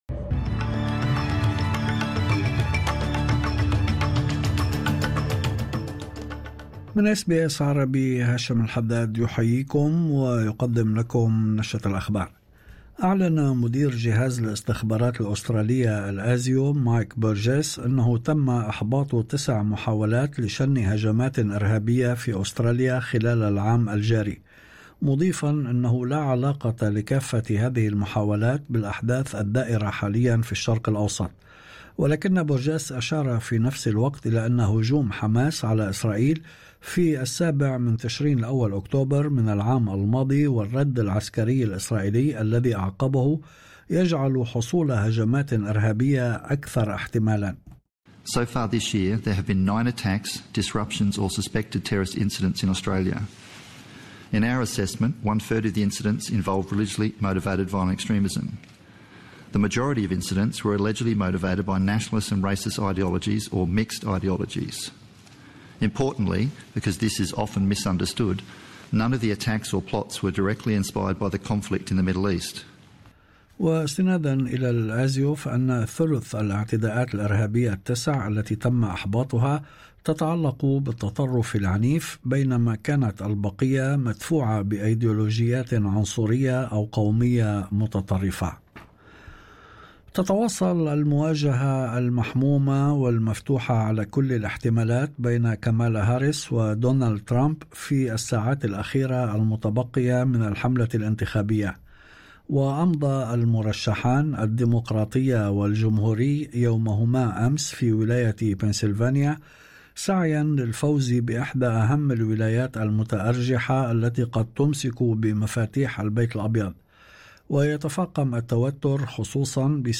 نشرة أخبار الظهيرة 05/11/2024